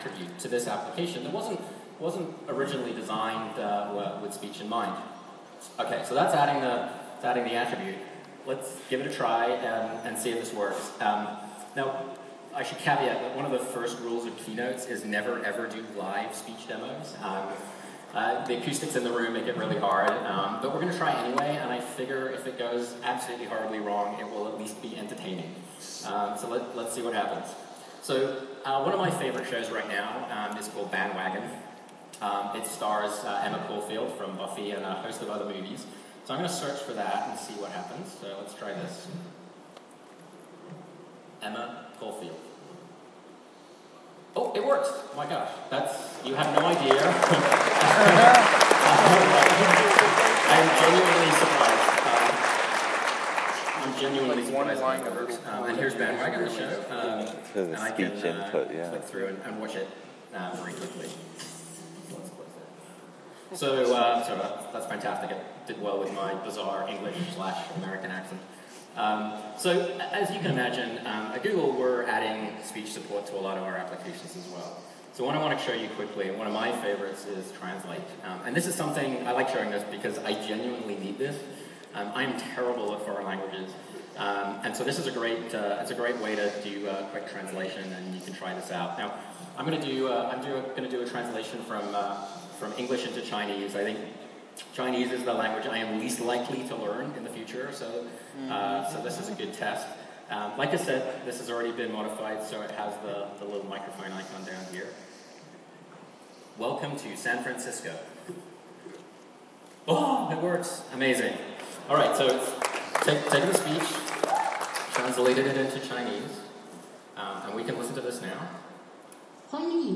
Google Chrome adding speech input keynote demo.